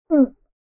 male_drown4.ogg